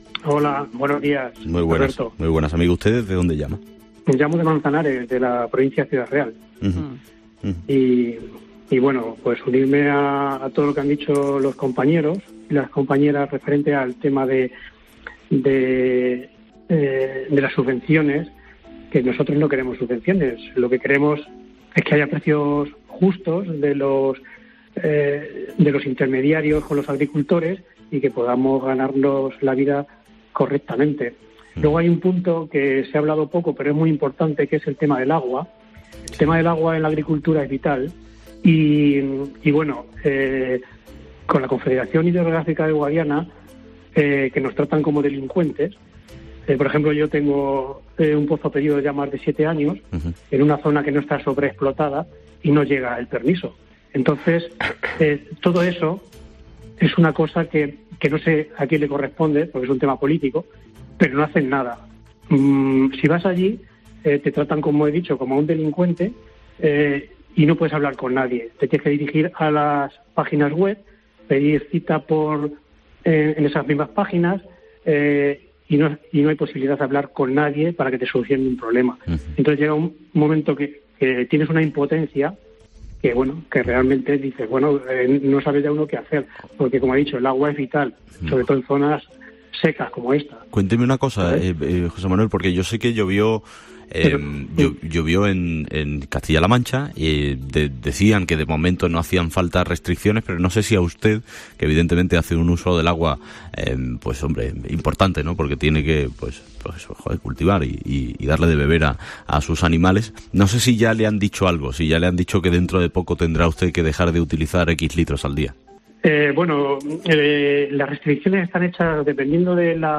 Agricultores de Castilla La Mancha llaman a Herrera en COPE